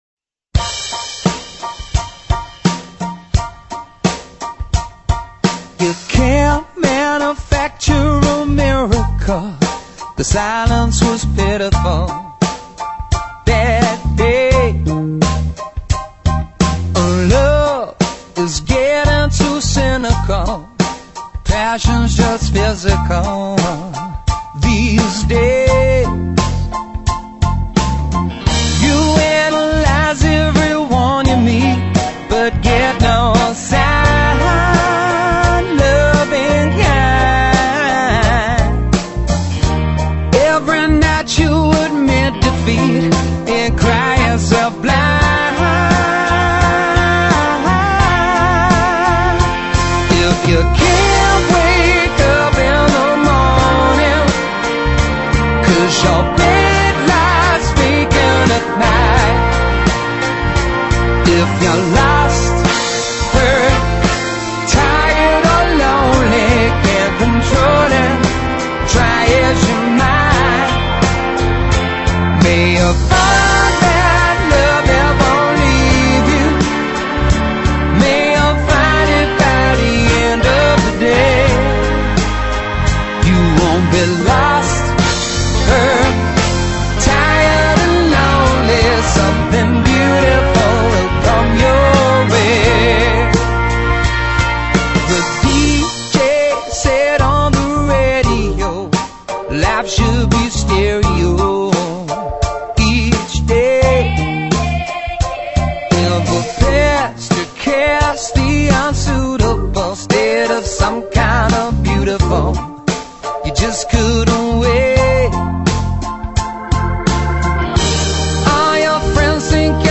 英语歌曲